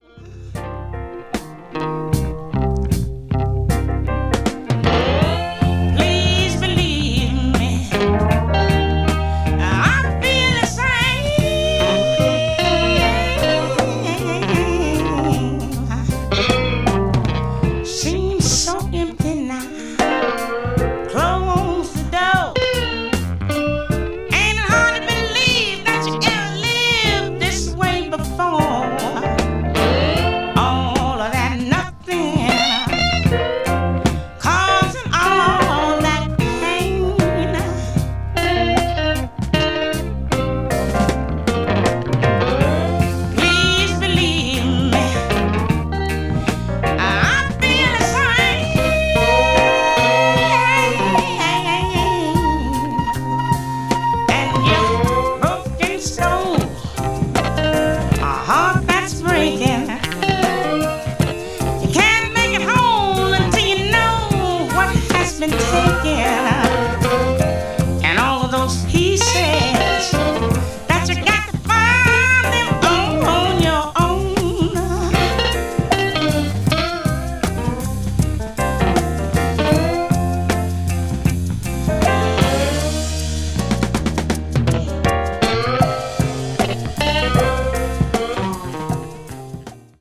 とにかく素晴らしい音質。ブルース・フィーリングあふれるA1、ダンサブルなソウル・グルーヴB1がなかでも大推薦です。
blues jazz   jazz funk   jazz vocal   soul jazz